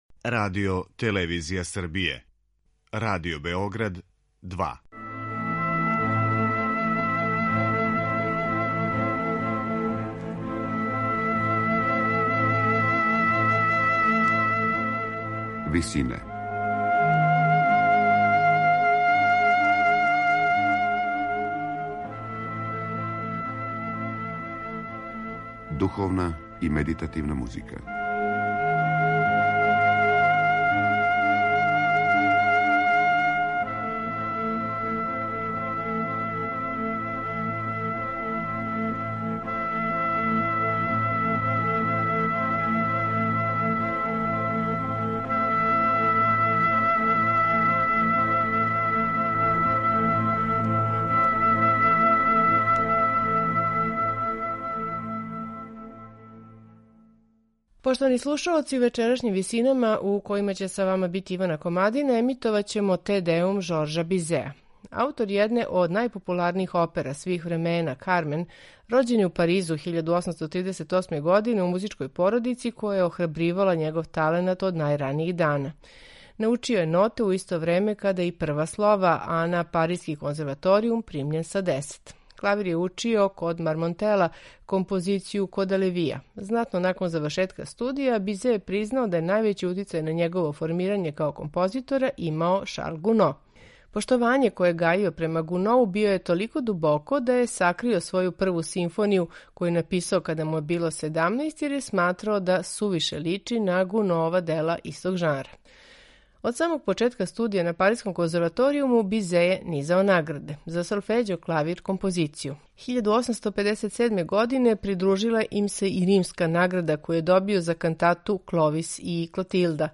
Сасвим очекивано, највећи део партитуре ове композиције прожет је оперским духом, што се одразило и на деонице солиста, које су технички неупоредиво захтевније од хорских.
сопран
тенор
бас